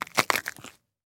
Хруст моркови при кусании, жевание, нарезка и другие звуки в mp3 формате
5. Хруст моркови, когда ее поломали пополам